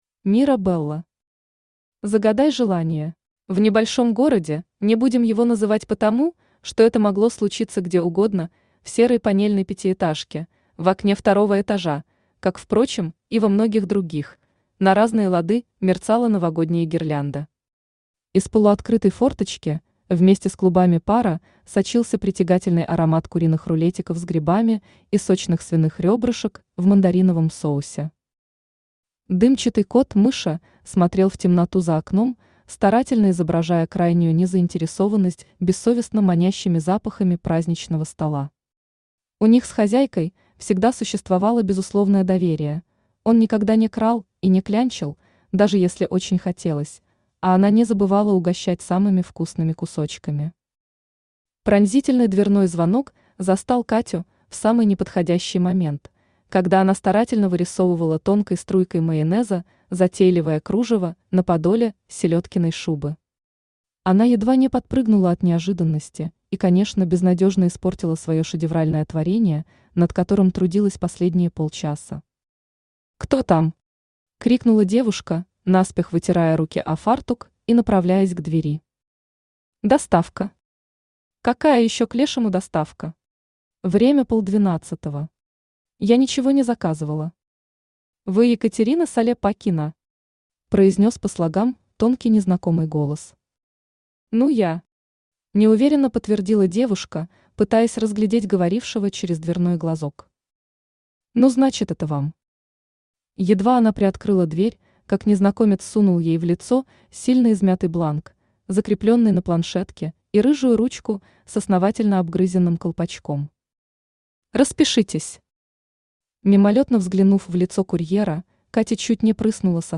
Аудиокнига Загадай желание | Библиотека аудиокниг
Aудиокнига Загадай желание Автор Мира Белла Читает аудиокнигу Авточтец ЛитРес.